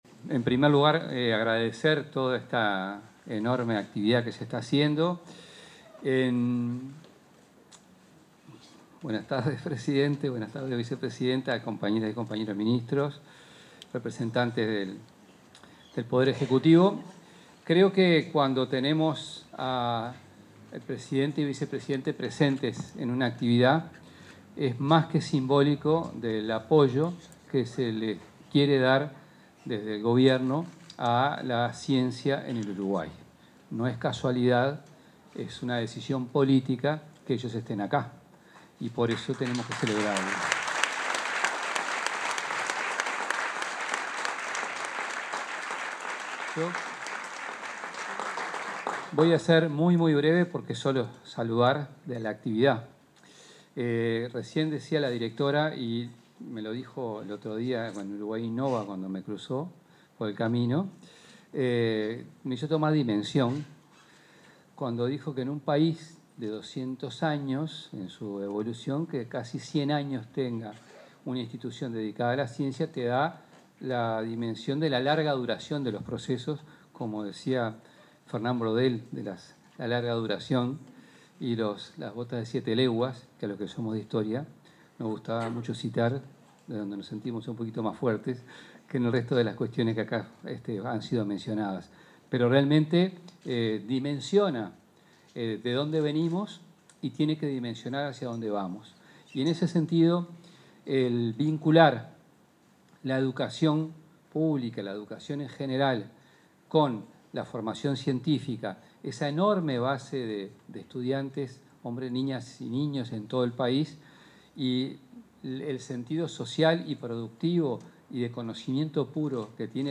El ministro de Educación y Cultura, José Carlos Mahía, expuso en el acto de celebración del Día de la Investigación, la Ciencia y la Tecnología, que